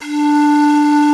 D3FLUTE83#01.wav